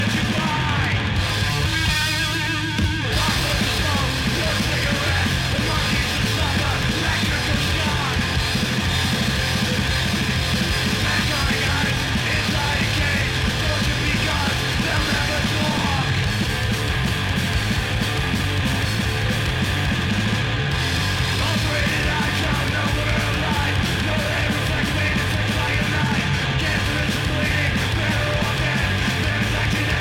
até irromperem os riffs downtuned, thrashy
alternados entre solos curtos e d-beats implacáveis
principalmente dentro da lógica do crust punk